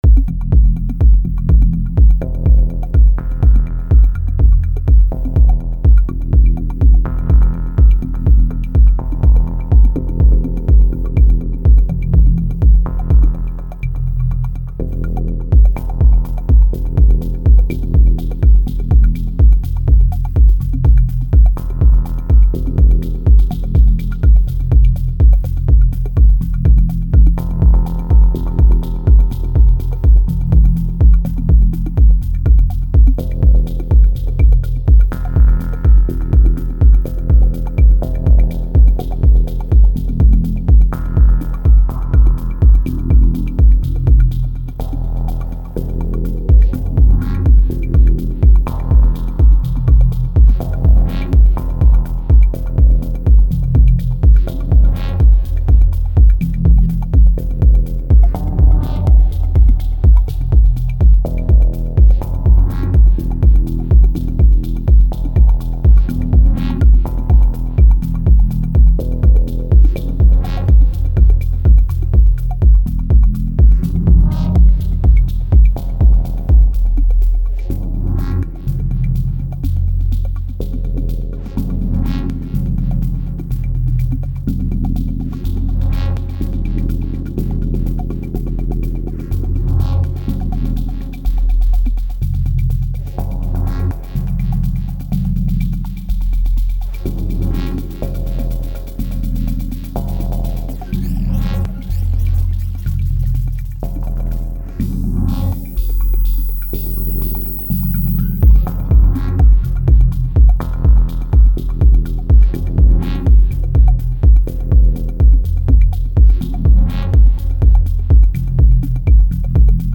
Toy Techno workout
T5 and 7 are the slow attack stabs
T6 is a surprise bell towards the end
T8 is noise crackle
There is notch filter on the FX track with slow lfo on cutoff and the verb is going through it.
I use the delay to 100 feedback and low cutting it for the build up, and some gentle use of ctrl all.